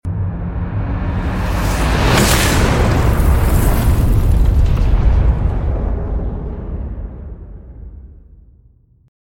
Meteorite Impact Sound FX (NO Sound Effects Free Download